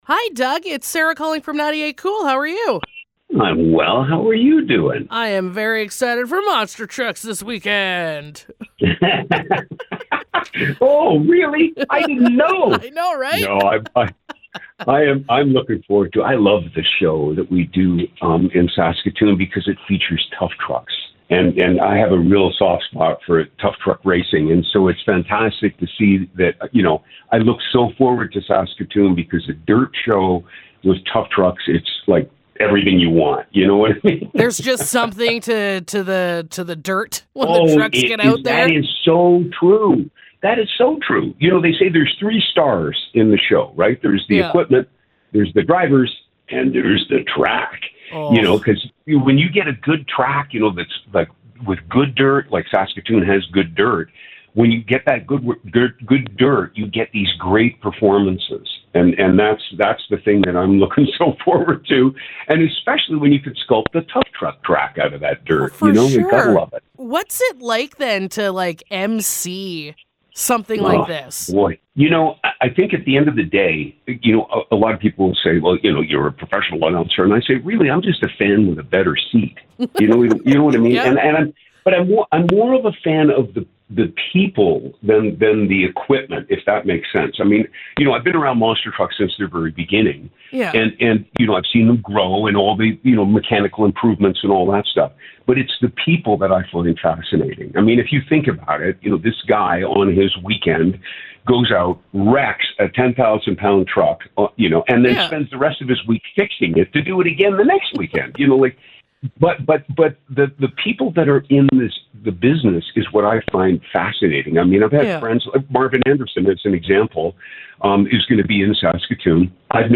Interview: RAM Motorsports Spectacular!